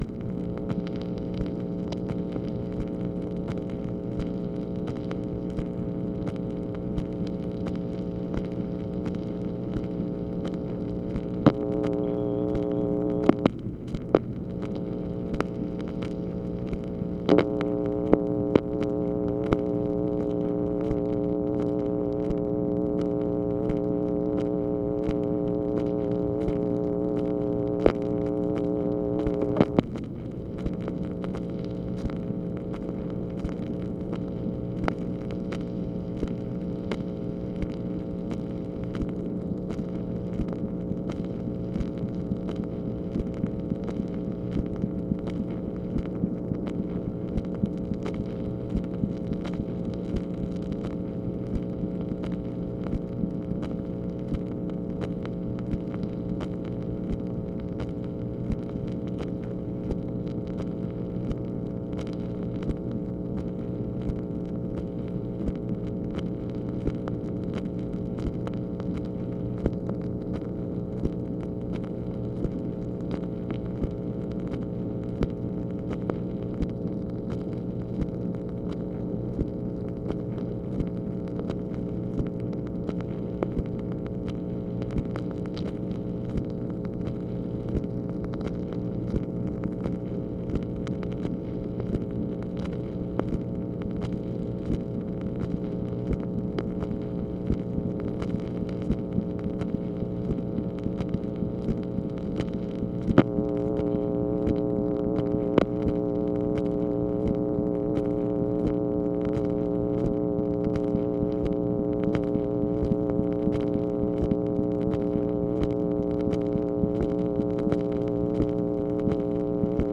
MACHINE NOISE, February 15, 1965
Secret White House Tapes | Lyndon B. Johnson Presidency